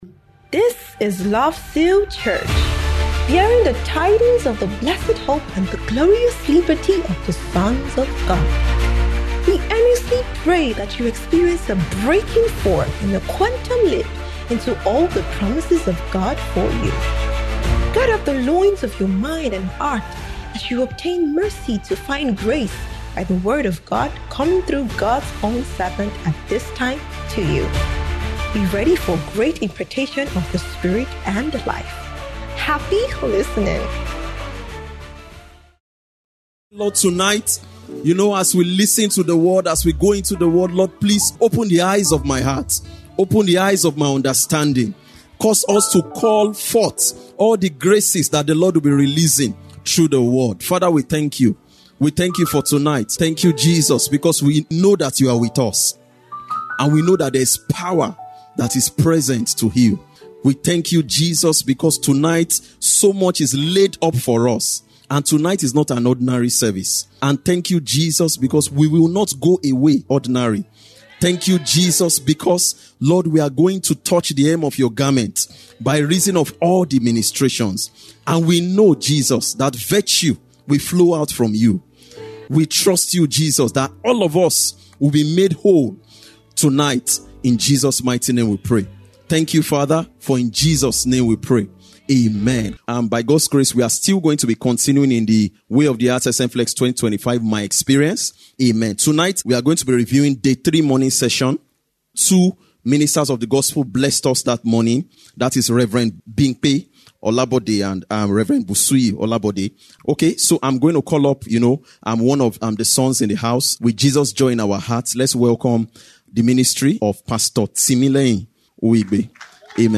WOTHSMFLX'25 Review V & Special Teaching